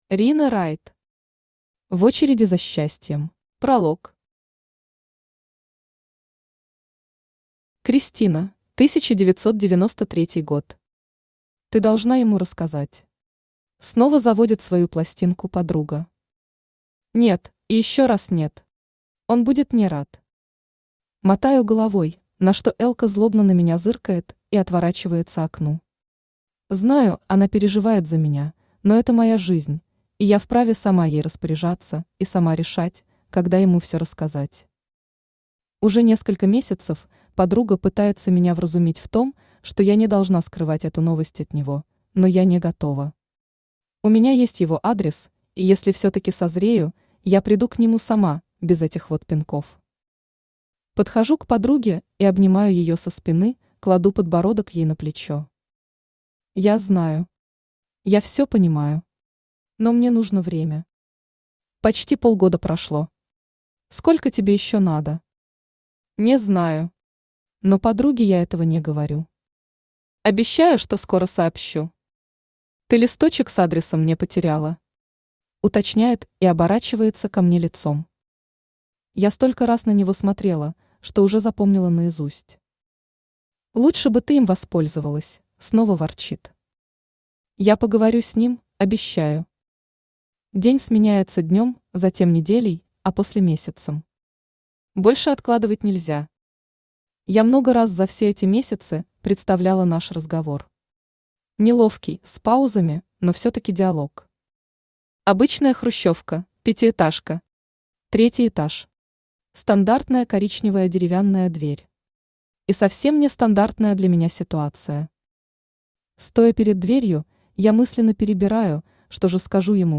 Аудиокнига В очереди за счастьем | Библиотека аудиокниг
Aудиокнига В очереди за счастьем Автор Рина Райт Читает аудиокнигу Авточтец ЛитРес.